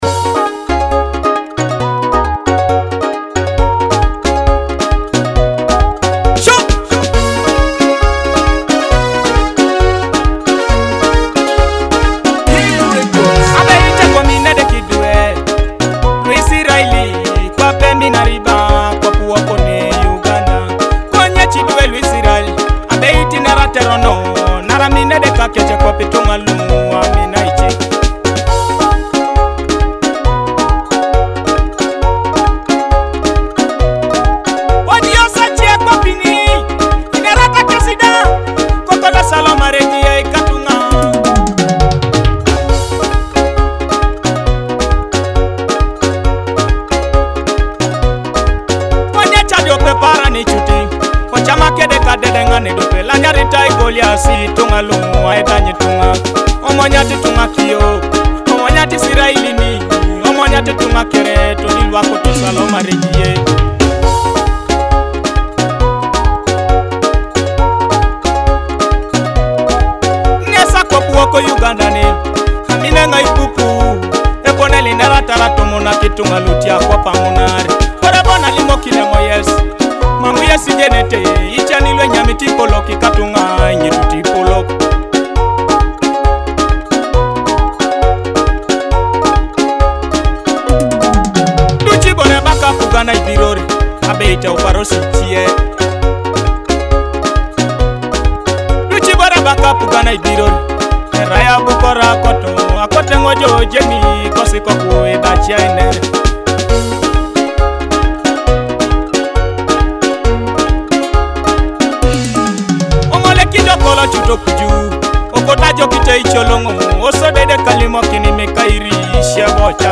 With its intense beats and evocative lyrics